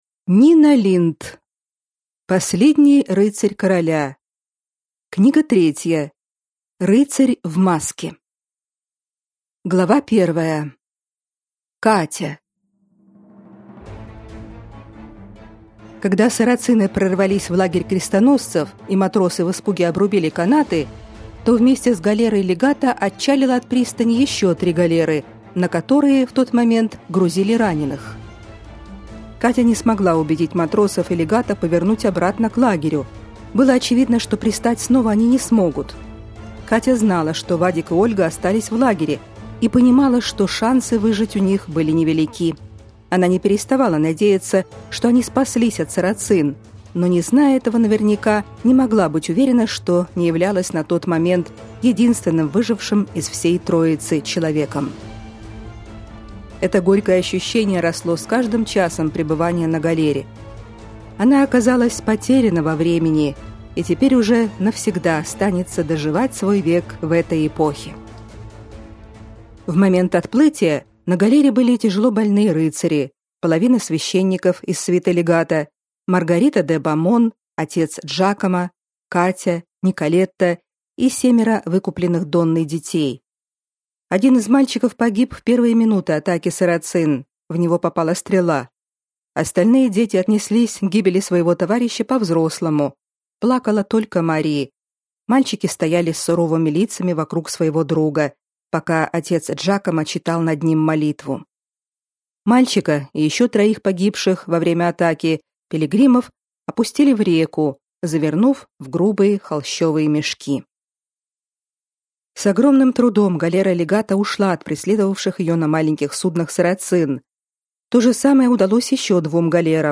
Аудиокнига Рыцарь в маске | Библиотека аудиокниг